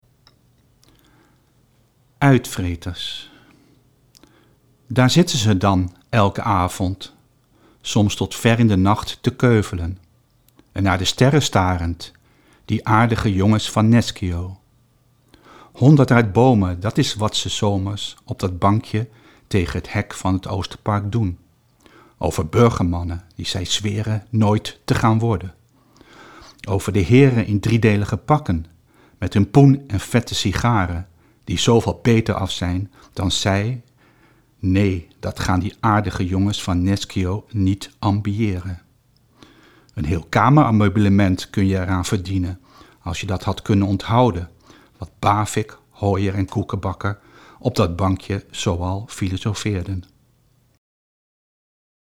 Het beeld ‘Titaantjes’ inspireerde me tot het schrijven van een gedicht, dat ik hier voordraag: